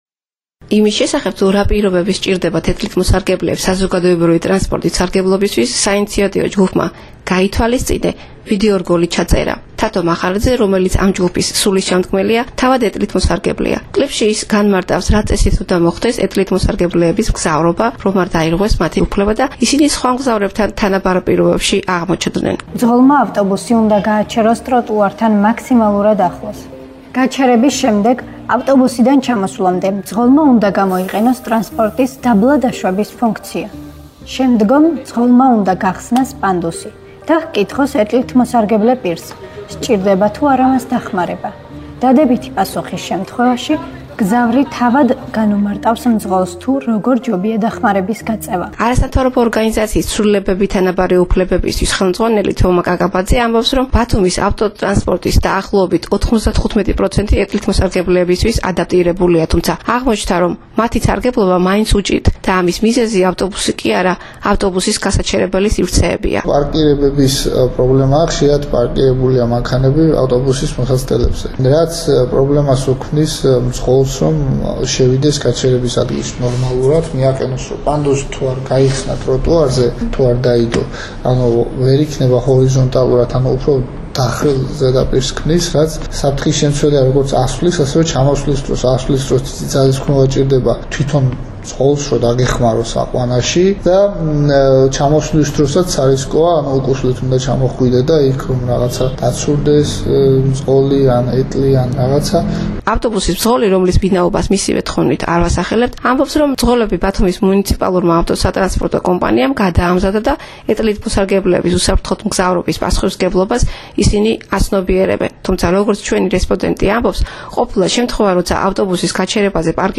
რადიო "თბილისის" საგამოძიებო-საზოგადოებრივი პროექტი "სატრანსპორტო ლაბირინთი"